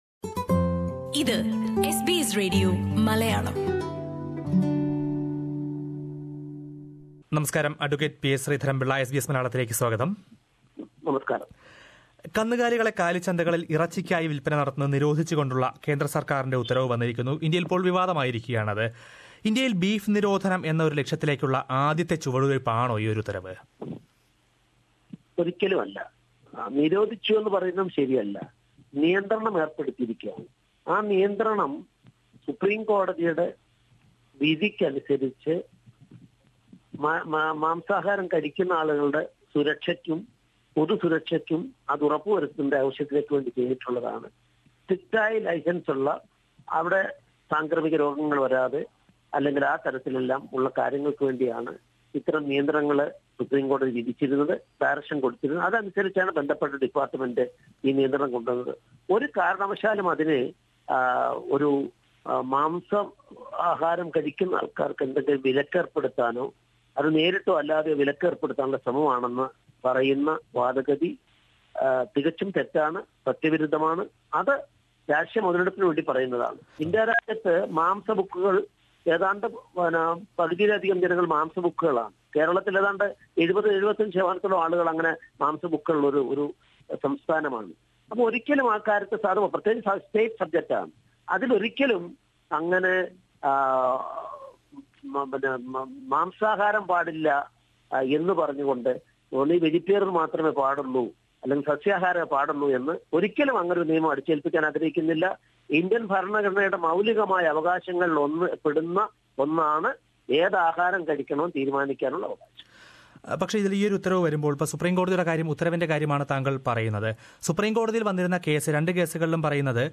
ഇന്ത്യയിൽ കാലിച്ചന്തകൾവഴി കശാപ്പിനായി കന്നുകാലികളെ വിൽക്കുന്നത് തടഞ്ഞ കേന്ദ്രസർക്കാർ വിജ്ഞാപനം സജീവ ചർച്ചയായിരിക്കുകയാണ്. ഇതേക്കുറിച്ച് ബി ജെ പിയുടെ പ്രമുഖ നേതാവും, കേരളഘടകത്തിൻറെ മുൻ പ്രസിഡൻറുമായ അഡ്വ. പി എസ് ശ്രീധരൻപിള്ളയുമായി എസ് ബി എസ് മലയാളം റേഡിയോ സംസാരിച്ചു.